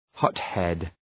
Προφορά
{‘hɒt,hed}